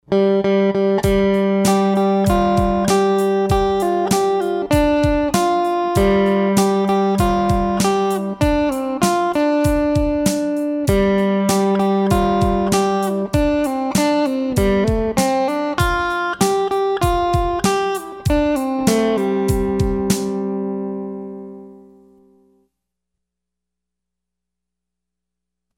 Rock Freebies